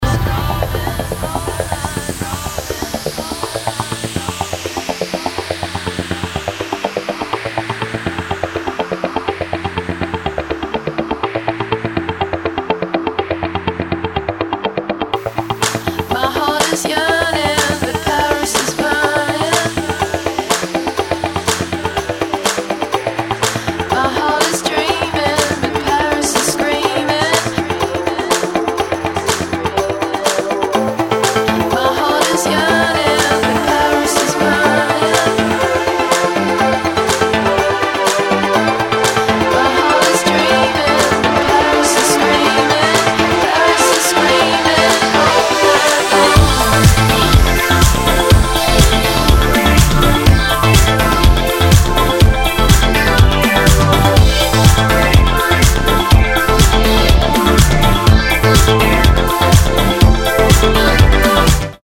House
French House
This part is the build up and drop.